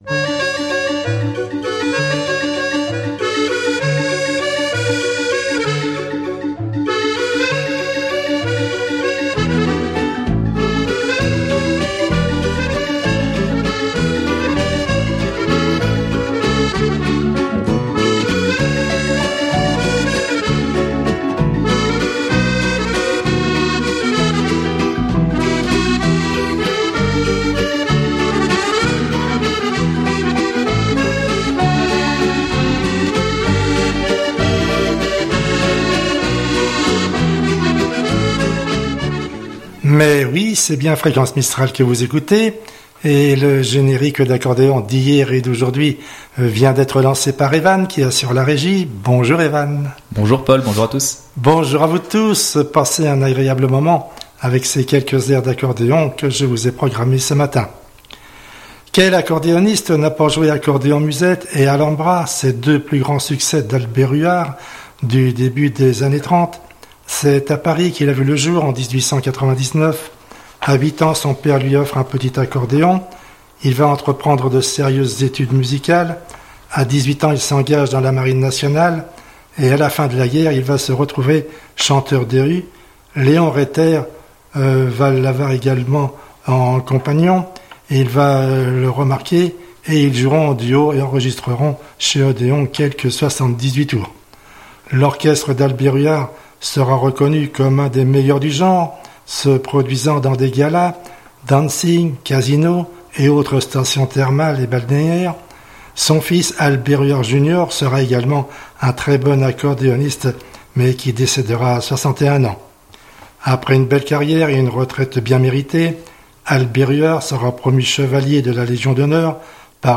Accordéon